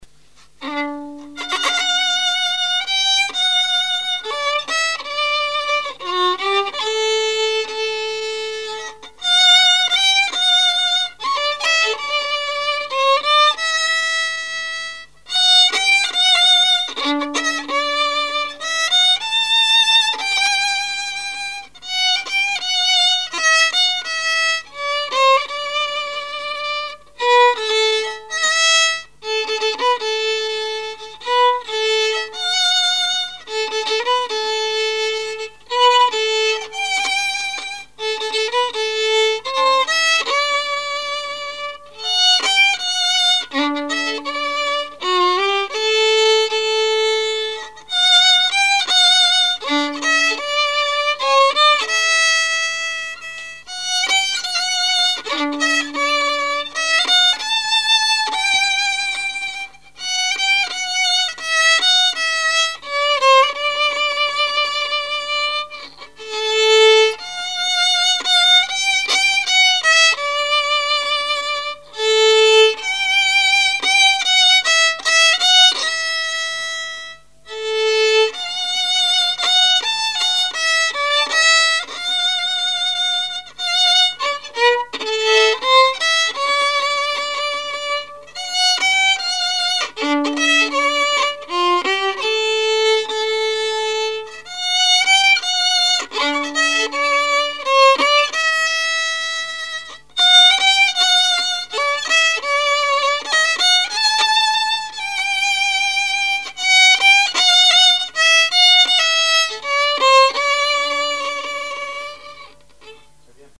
musique varieté, musichall
Répertoire musical au violon